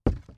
WoodFootsteps
ES_Walk Wood Creaks 9.wav